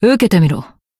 BA_V_Mina_Battle_Shout_1.ogg